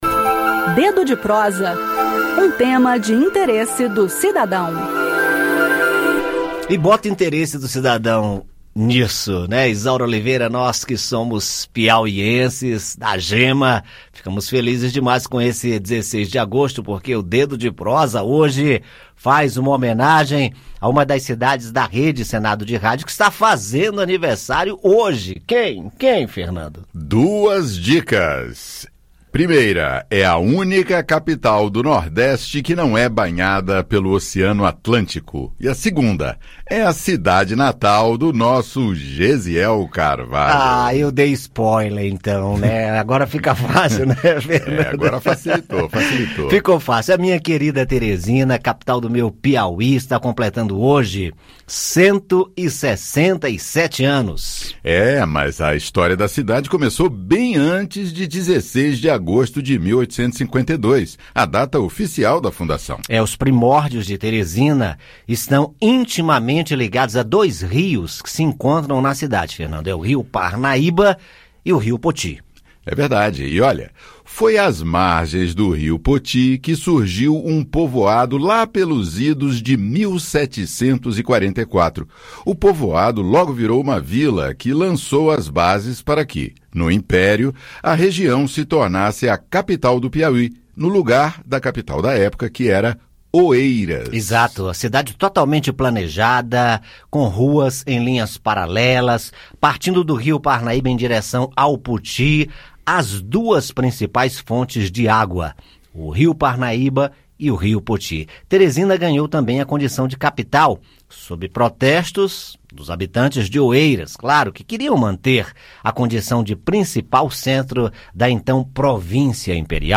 No "Dedo de Prosa" desta sexta-feira, 16 de agosto, o tema é o aniversário de 167 anos, celebrado hoje, da cidade de Teresina, capital do estado do Piauí. Ouça o áudio com o bate-papo.